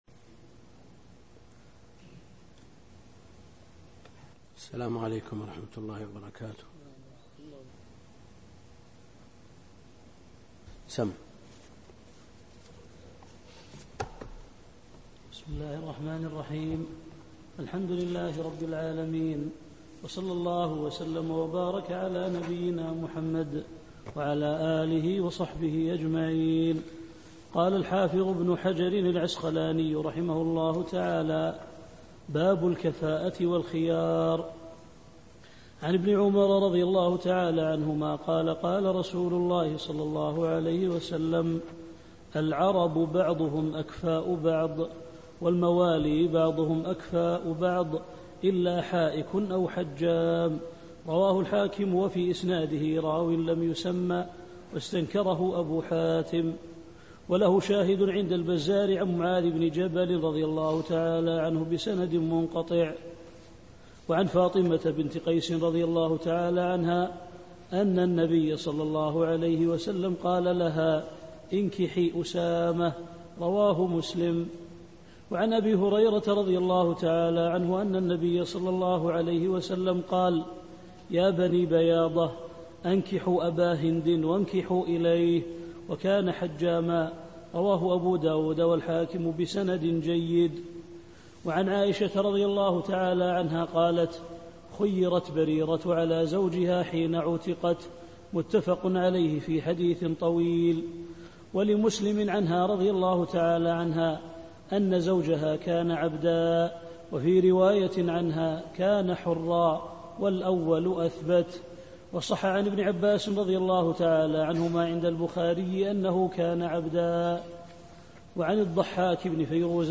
الدرس السادس من دروس شرح بلوغ المرام كتاب النكاح للشيخ عبد الكريم الخضير